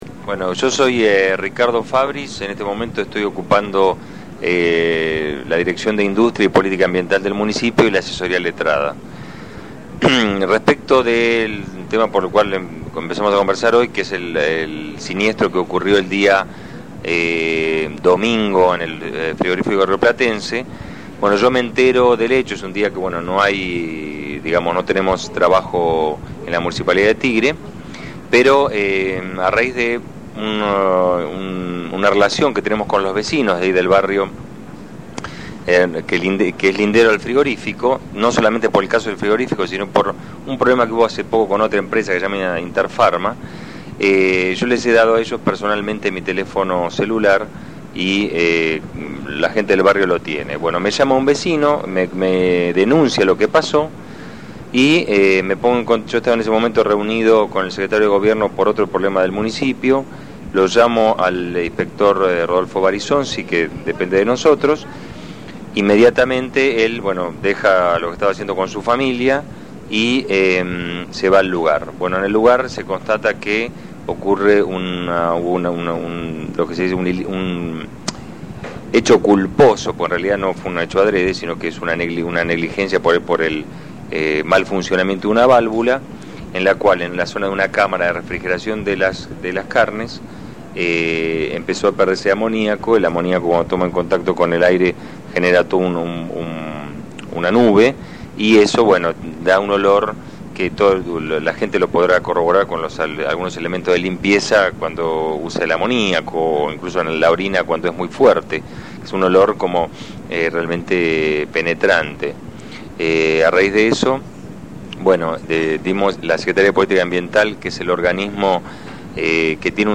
Audio:explicación de Ricardo Fabris